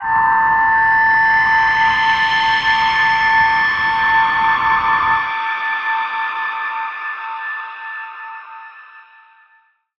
G_Crystal-A6-f.wav